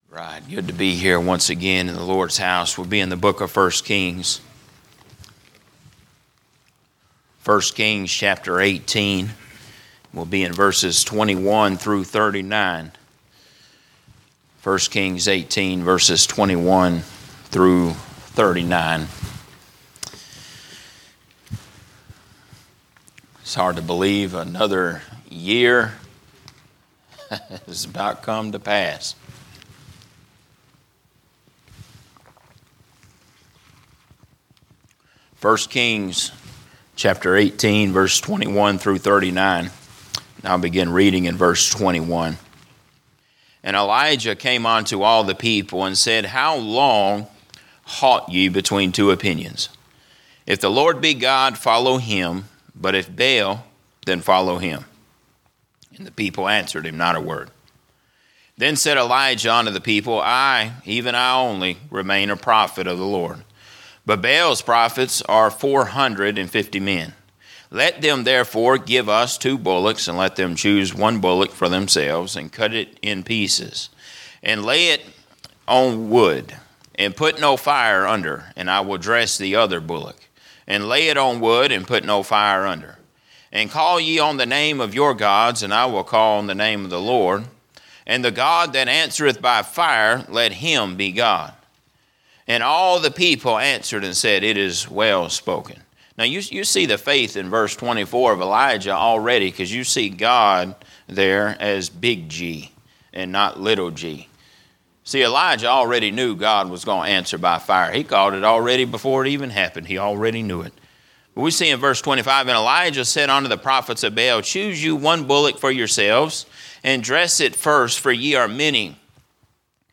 " General Preaching " Sermons not part of a specific series